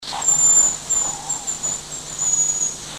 Рябчик
Крик